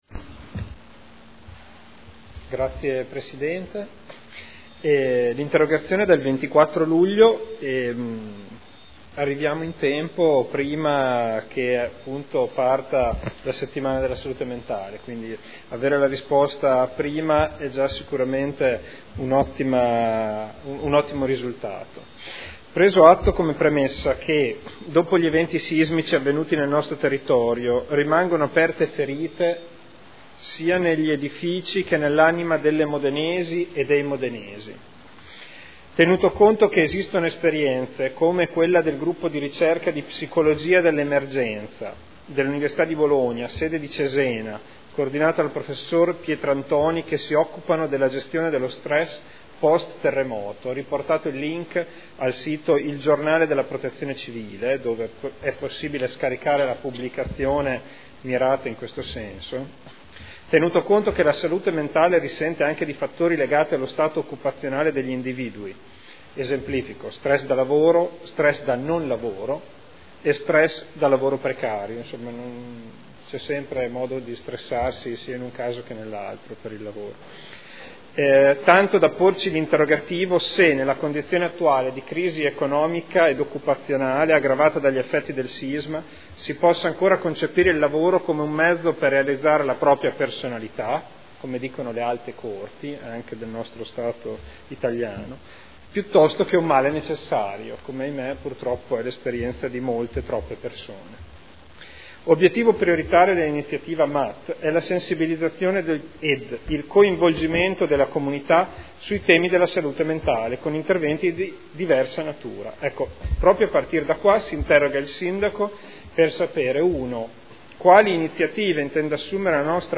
Federico Ricci — Sito Audio Consiglio Comunale
Interrogazione del consigliere Ricci (Sinistra per Modena) avente per oggetto: ““MAT” settimana della salute mentale – Modena – 19-27 ottobre 2012”